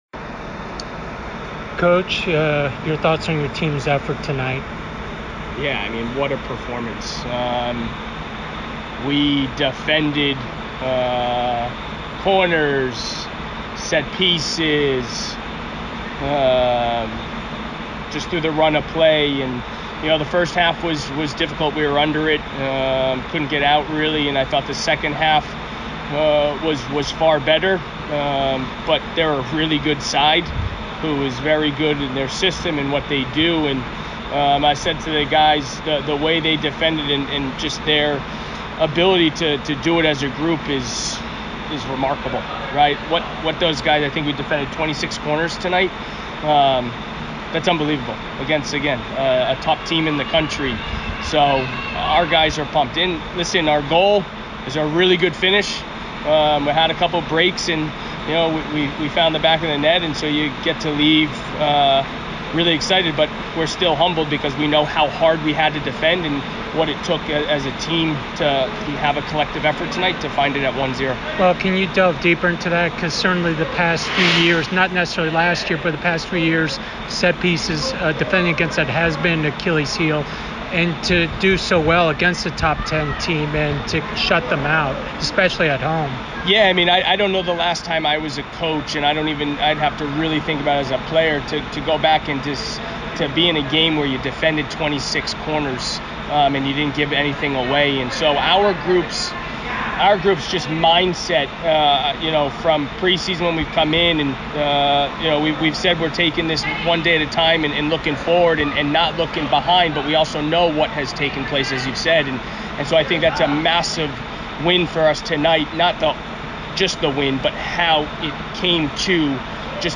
UNH_postgame.mp3